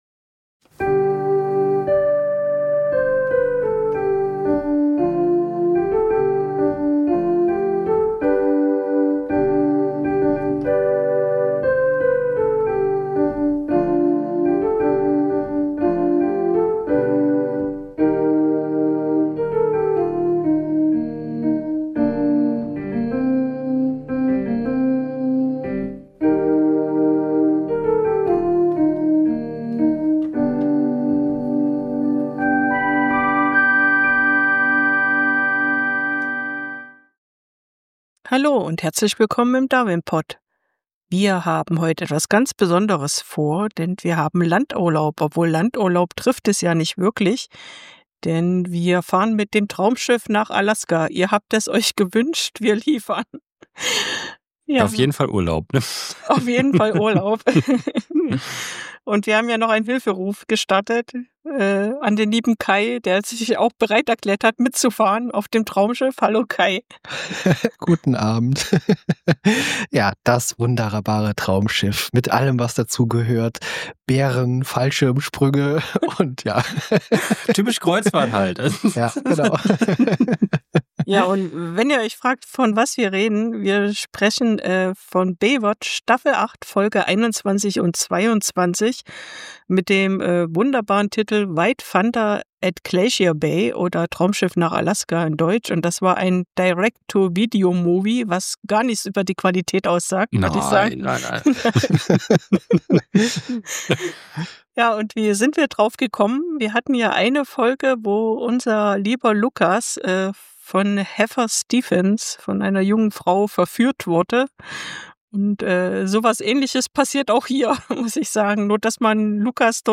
Ein Fiebertraum, ein unfassbar schlechtes Stück TV - und vielleicht die Besprechung, in der wir bislang am meisten gelacht haben.